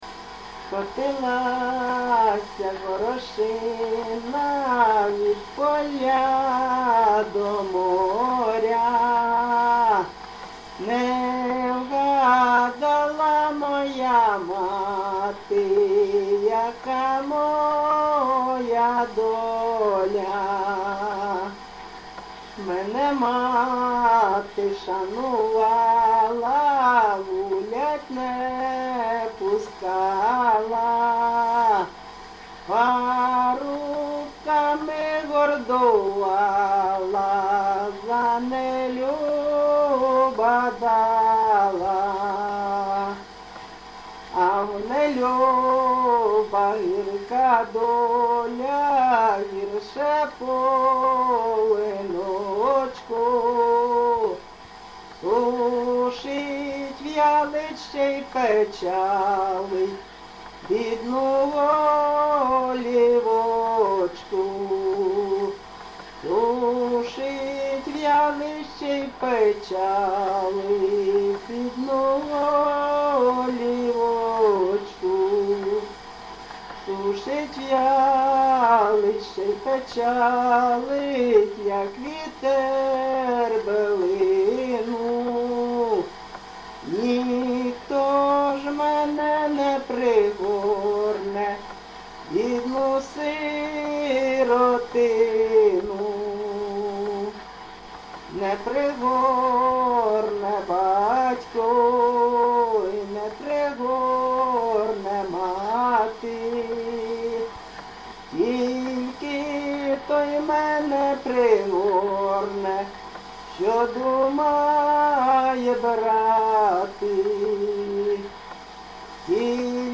ЖанрПісні з особистого та родинного життя
Місце записус. Олександро-Калинове, Костянтинівський (Краматорський) район, Донецька обл., Україна, Слобожанщина